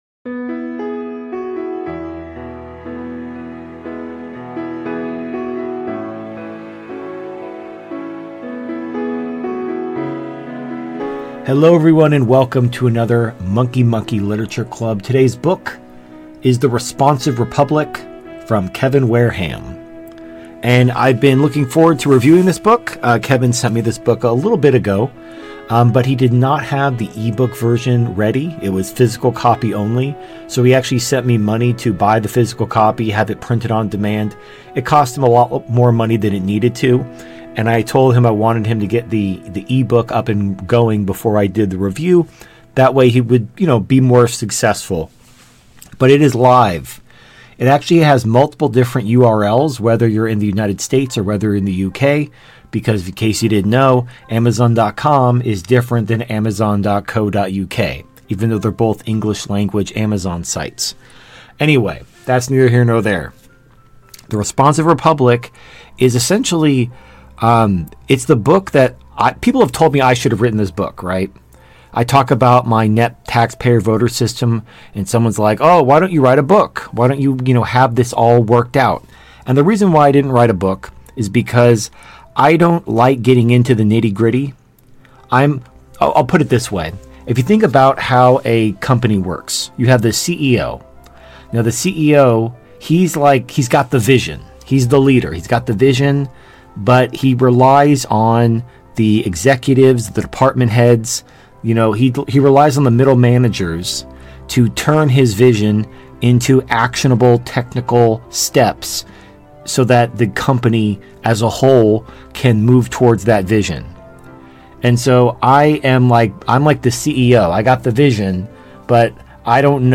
Book Review: